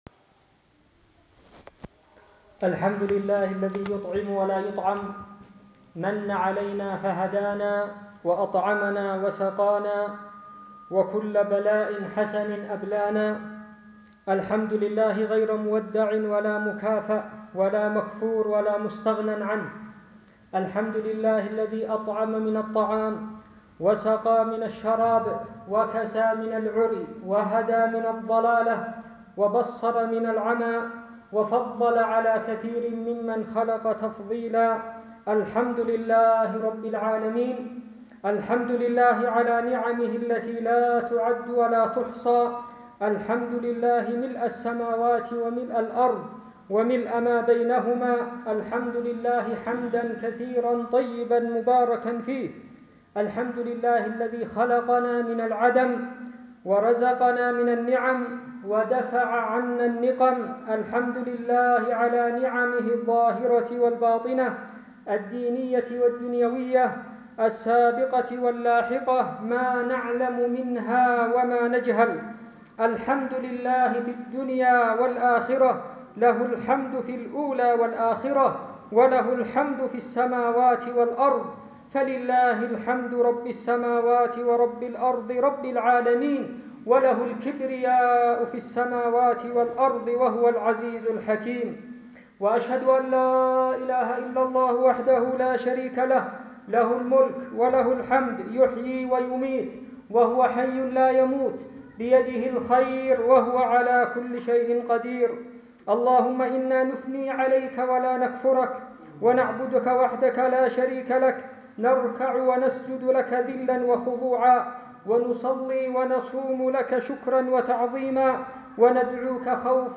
عبادة الشكر (محاضرة متميزة كثيرة الفوائد والمواعظ)